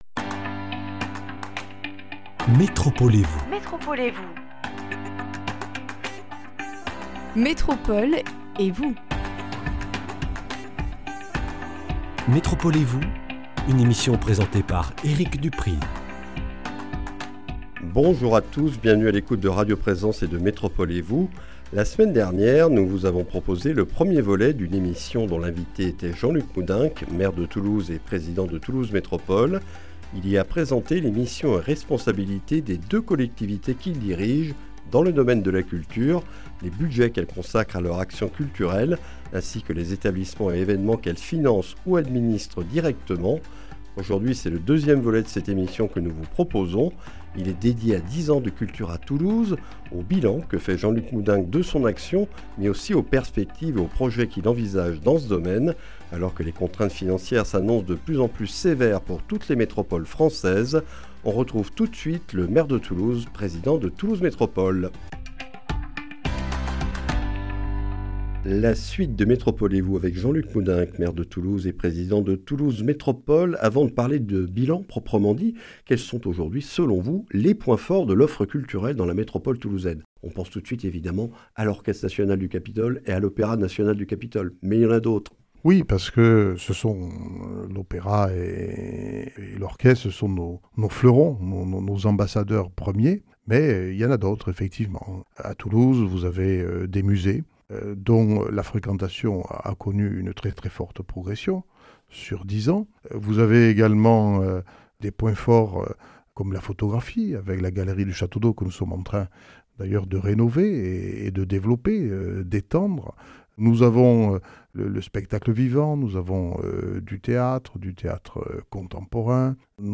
Nous retrouvons Jean-Luc Moudenc, maire de Toulouse et président de Toulouse Métropole, pour le second volet d’une émission consacrée au bilan de son action et de sa politique dans le domaine de la culture depuis 2014. Dans ce numéro, il évoque les réussites et points forts de l’offre culturelle à Toulouse et dans la Métropole, fait le point sur les projets de classement du centre de Toulouse à l’Unesco et de construction d’un auditorium, et explique ses choix concernant le soutien aux structures culturelles en 2025, dans un contexte de baisse des dotations de l’État aux collectivités.